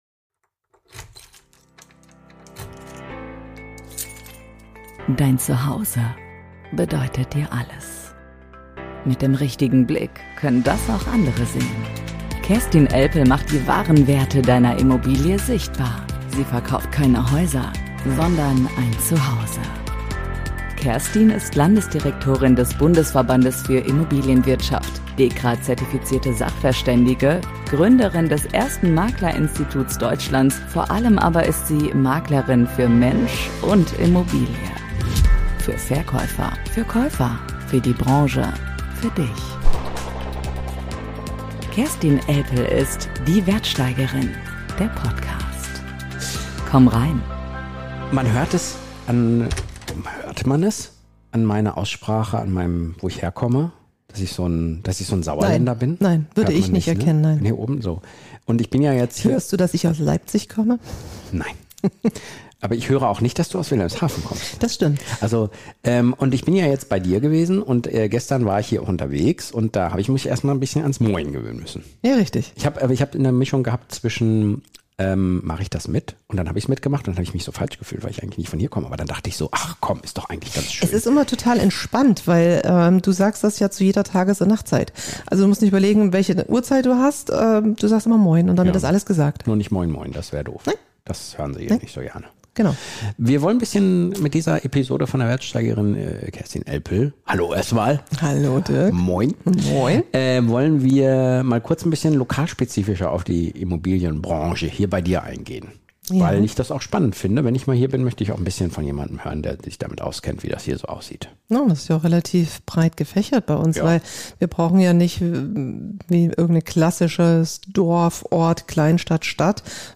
Ein persönliches, informatives und manchmal auch überraschendes Gespräch über Standortfaktoren, demografische Trends, Bodenrichtwerte und das Leben zwischen Marinehafen, Südstrand und Wattenmeer.